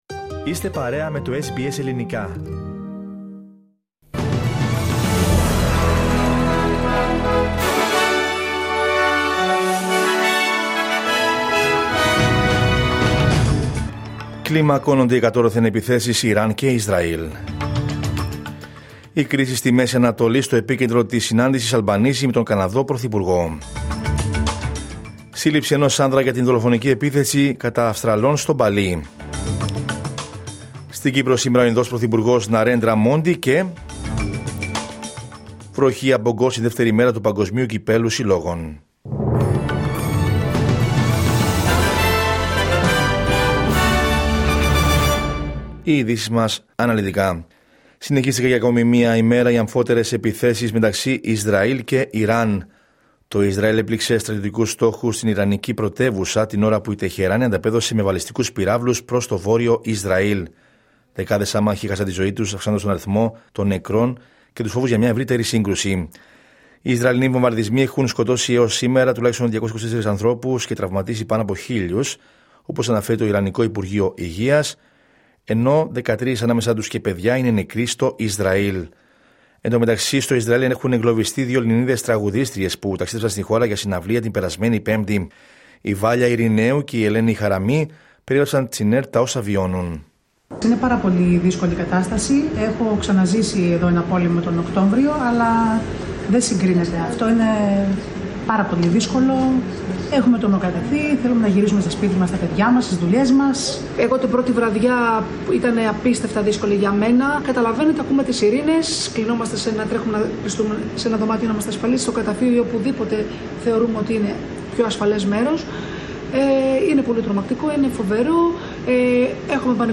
Δελτίο Ειδήσεων Δευτέρα 16 Ιουνίου 2025